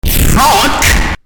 Play, download and share Obnoxious FUCK original sound button!!!!
obnoxious-fuck.mp3